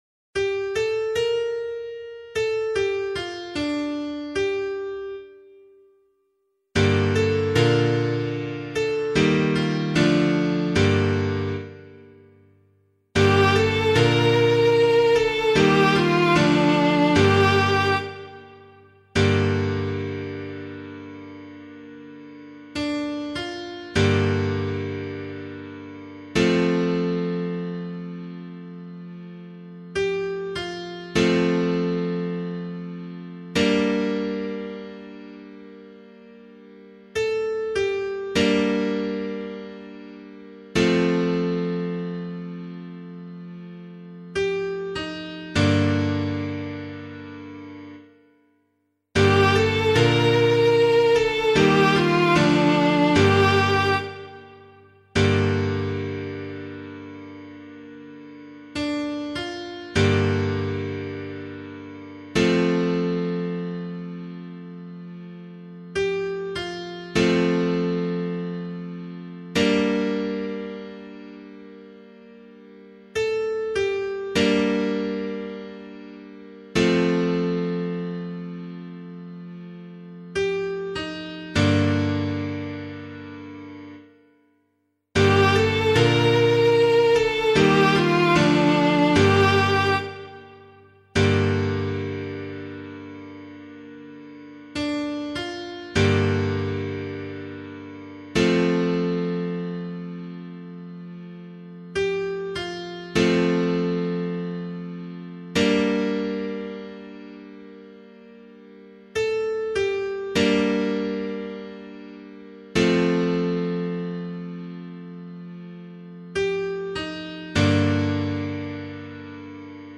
pianovocal
038 Ordinary Time 4 Psalm C [LiturgyShare 3 - Oz] - piano.mp3